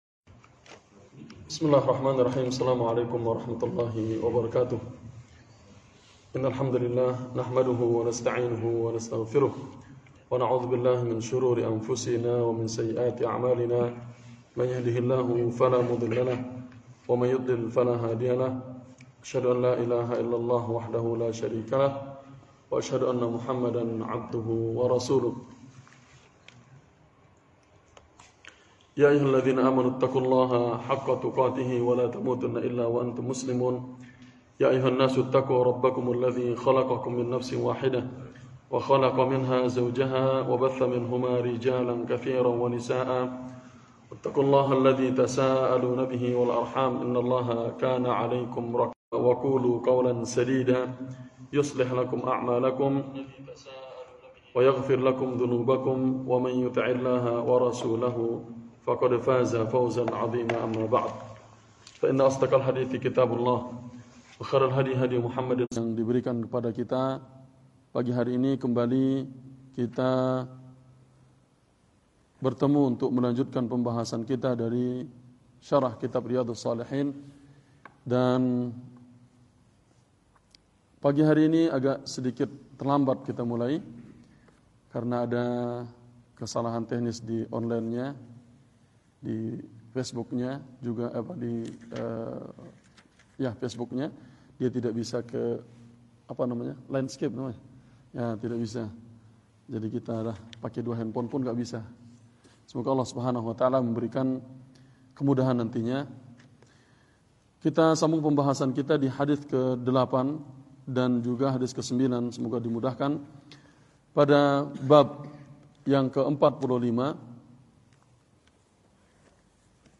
Daurah Al-Khor Sabtu Pagi – Masjid At-Tauhid Syarah Riyadhus Shalihin Bab 45